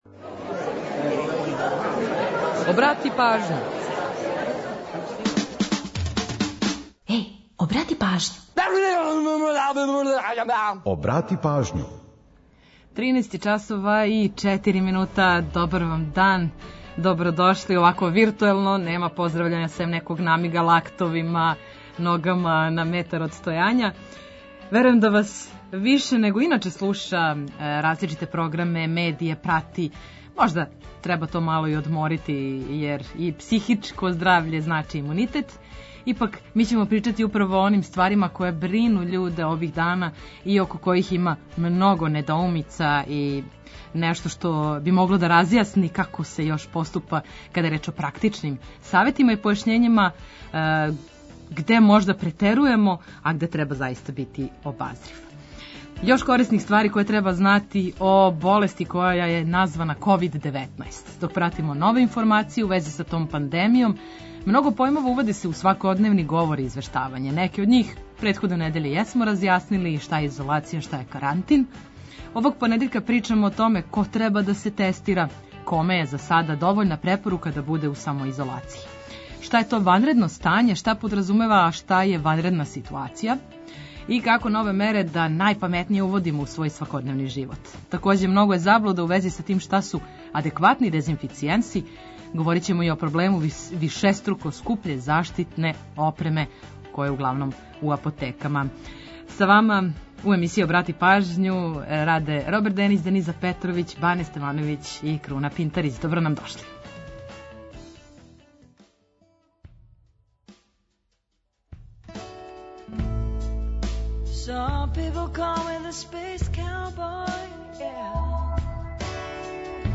Сервисне информације и наш репортер са подацима о саобраћају помоћи ће многима у организовању дана.
Ту је и пола сата резервисаних само за нумере из Србије и региона.